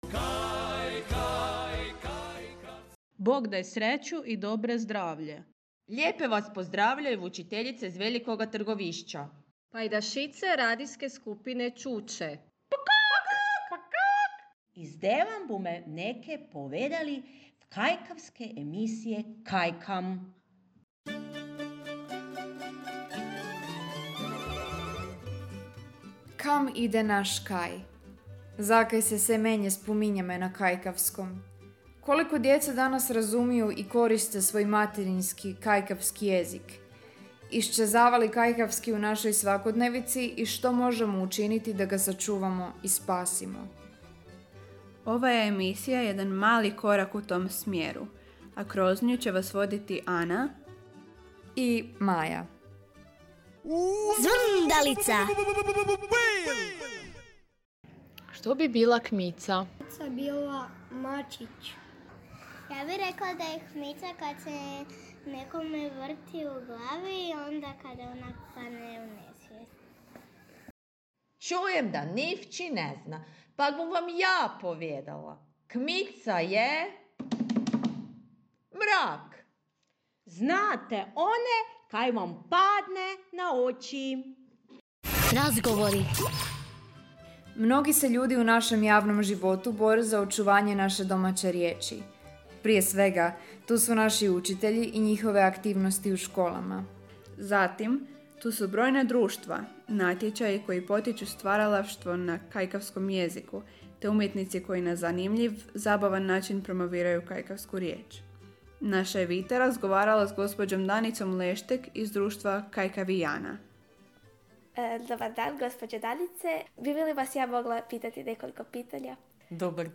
Ujedno su dobili zadatak svojim mobitelima snimiti ankete o poznavanju nekih kajkavskih riječi te preslušati na Meduzi predložene radijske emisije.
Sudionici su snimili ankete, najavnu i odjavu špicu.